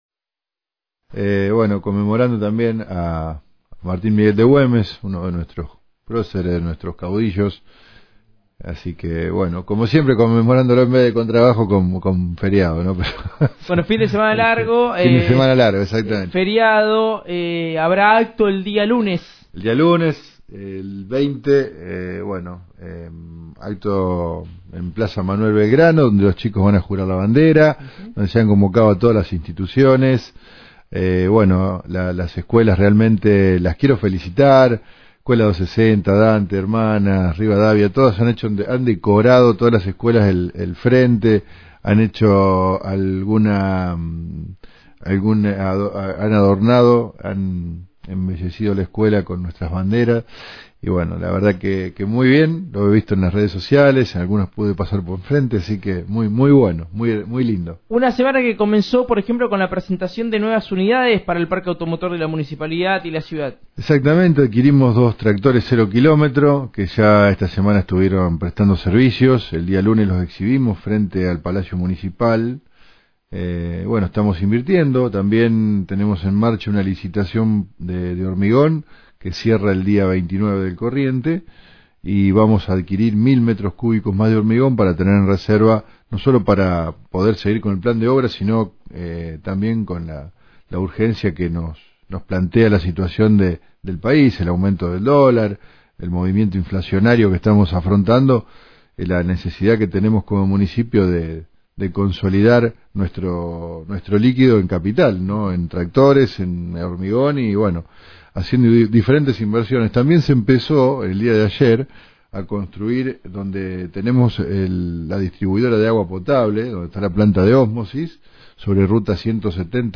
Nota Int. Javier Meyer.